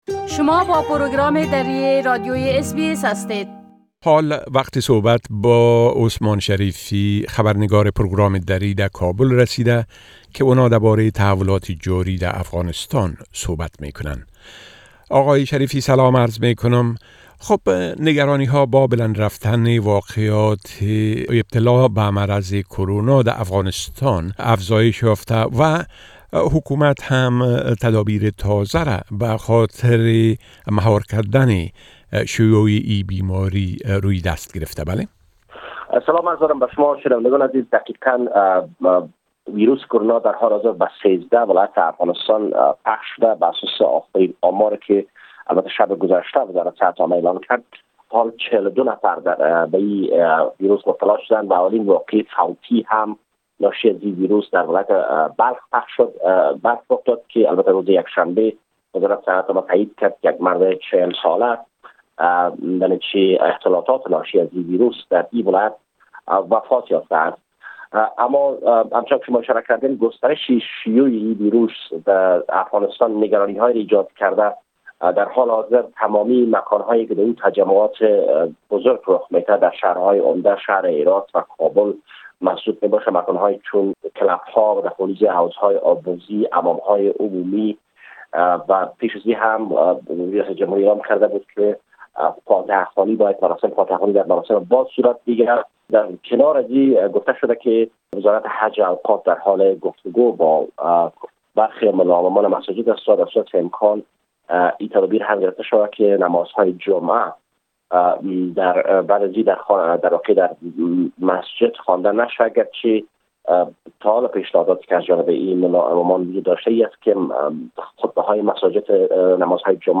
گزارش كامل خبرنگار ما در كابل بشمول اوضاع امنيتى٬ و تحولات مهم ديگر در افغانستان را در اينجا شنيده ميتوانيد.